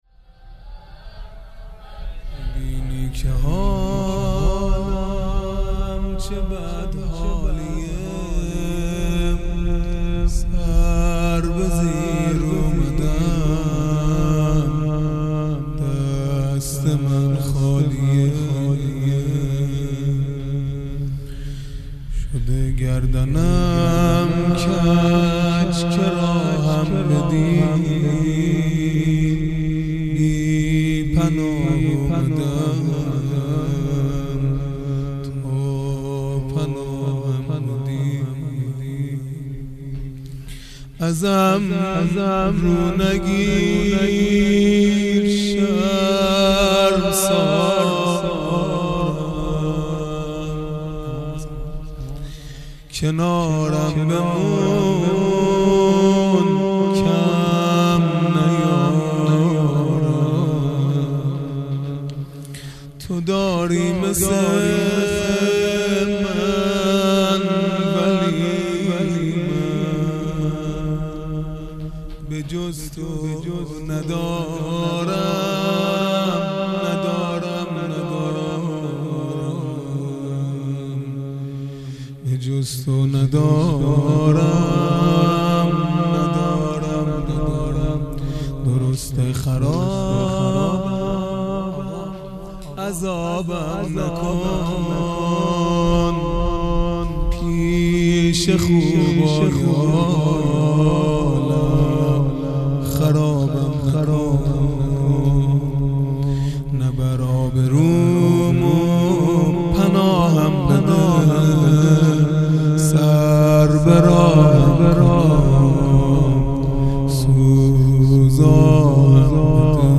خیمه گاه - هیئت بچه های فاطمه (س) - مناجات پایانی | میبینی که حالم چه بد حالیه
دهه اول محرم الحرام ۱۴۴٢ | شب هشتم